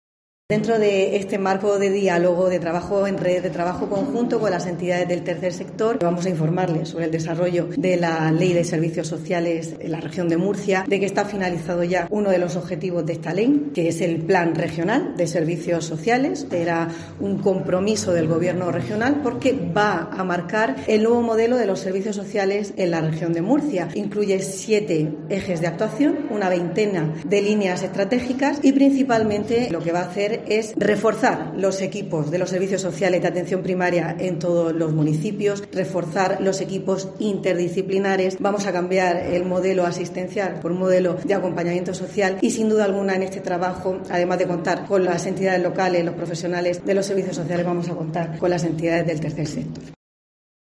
Conchita Ruiz, consejera de Política Social, Familias e Igualdad